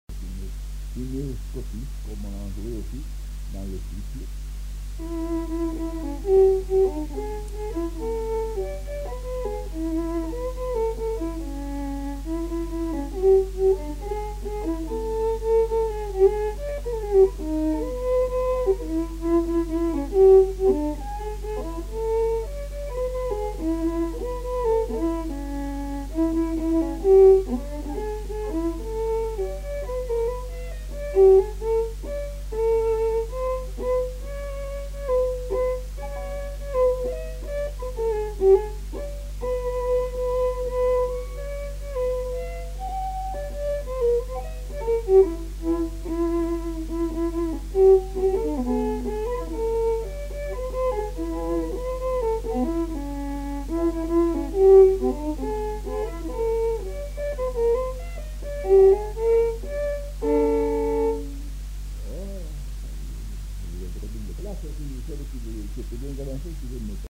Aire culturelle : Haut-Agenais
Lieu : Cancon
Genre : morceau instrumental
Instrument de musique : violon
Danse : rondeau